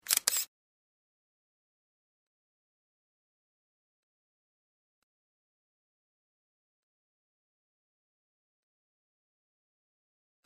Звуки скриншота
Звук скриншота как щелчок фотоаппарата